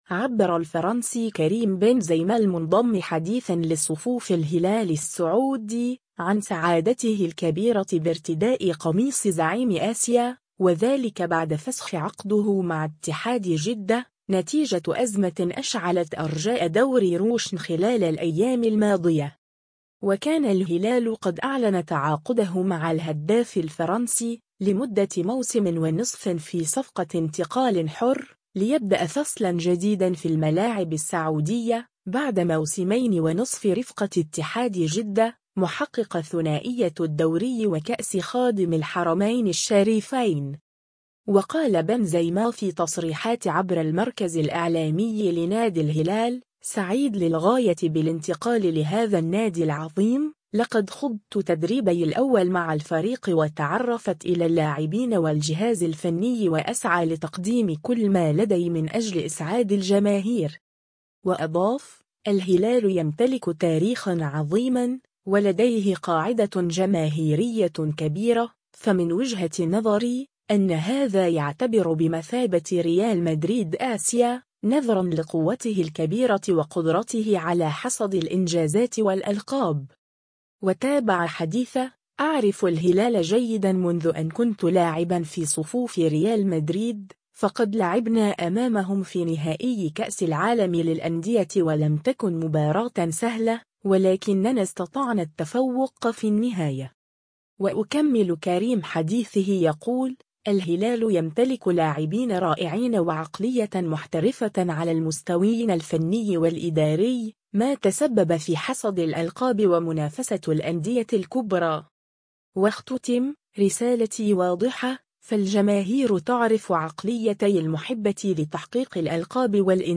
و قال بنزيما في تصريحات عبر المركز الإعلامي لنادي الهلال : “سعيد للغاية بالانتقال لهذا النادي العظيم، لقد خضت تدريبي الأول مع الفريق و تعرفت إلى اللاعبين و الجهاز الفني و أسعى لتقديم كل ما لدي من أجل إسعاد الجماهير”.